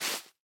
sounds / block / moss / step4.ogg
step4.ogg